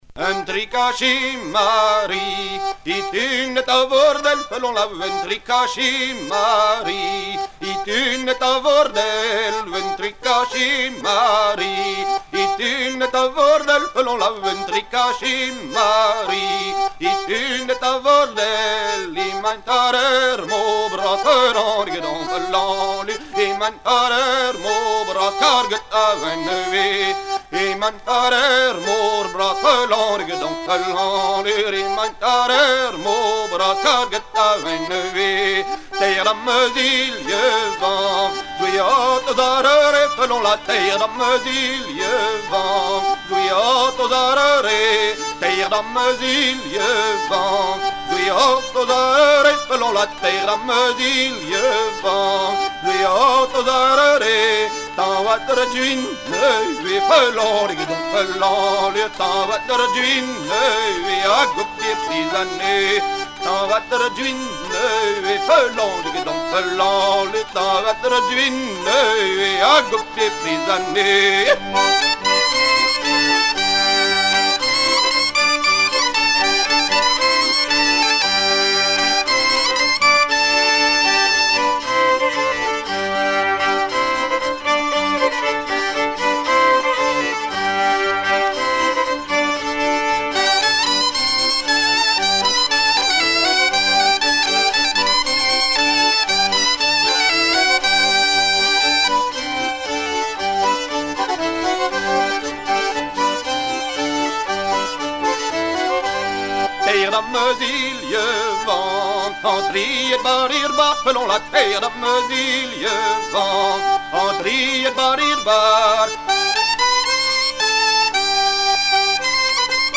gestuel : à virer au guindeau
circonstance : maritimes
Pièce musicale éditée